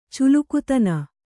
♪ culukutana